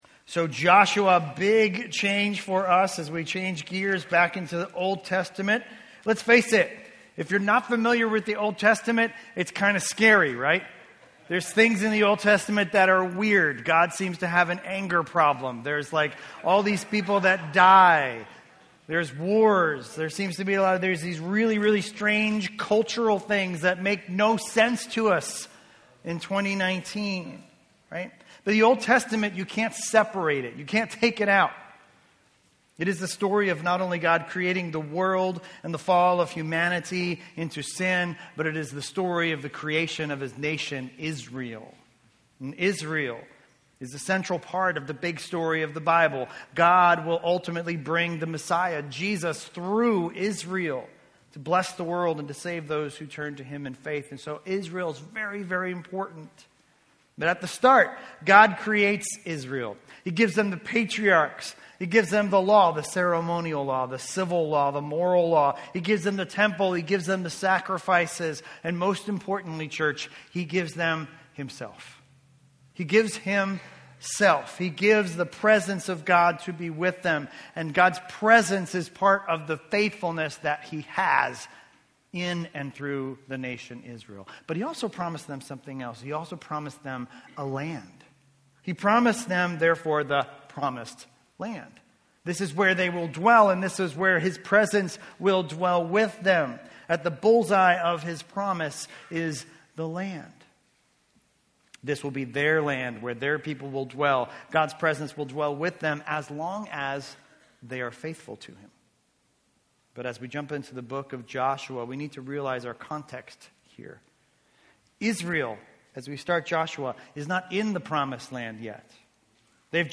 A message from the series "Joshua."